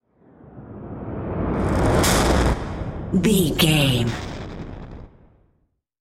Sound Effects
In-crescendo
Aeolian/Minor
ominous
eerie
Horror Synths
Horror Ambience